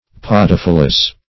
Search Result for " podophyllous" : The Collaborative International Dictionary of English v.0.48: Podophyllous \Pod`o*phyl"lous\, a. 1.